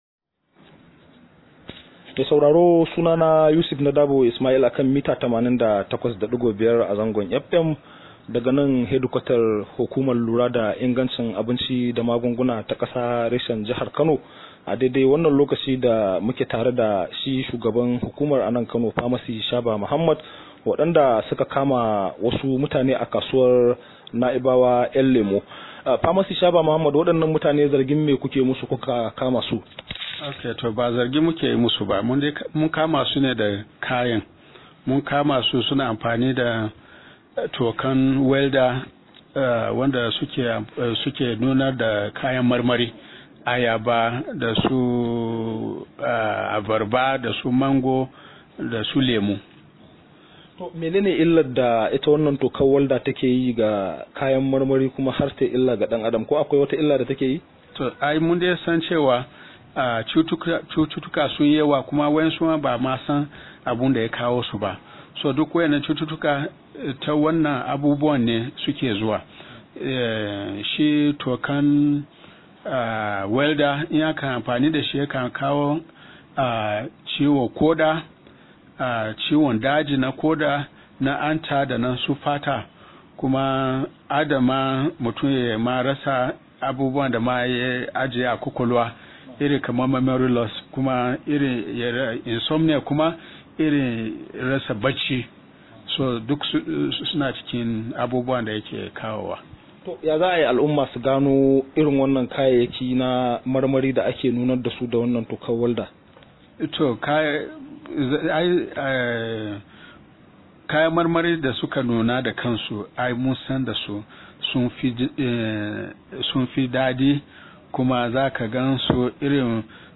Rahoto: NAFDAC ta kama ‘yan kasuwa dake zubawa kayan marmari hodar walda